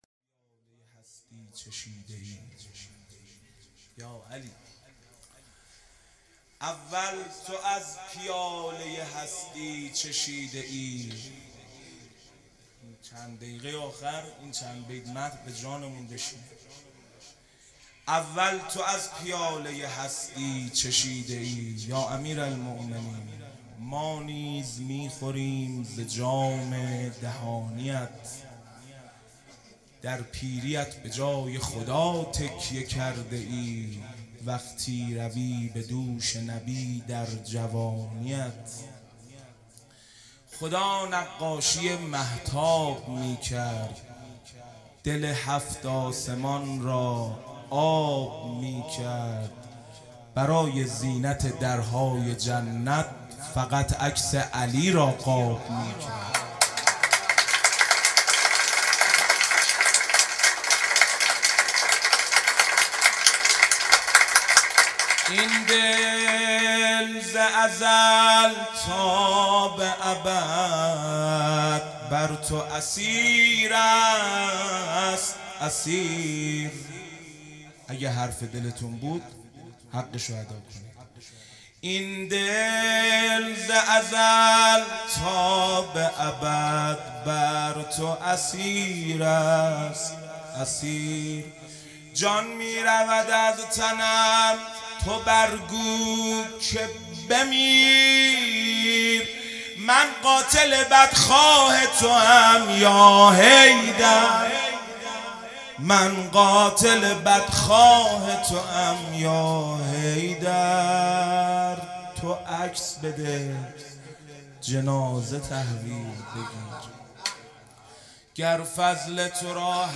هیئت دانشجویی فاطمیون دانشگاه یزد - مناجات پایانی